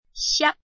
臺灣客語拼音學習網-客語聽讀拼-南四縣腔-入聲韻
拼音查詢：【南四縣腔】xiab ~請點選不同聲調拼音聽聽看!(例字漢字部分屬參考性質)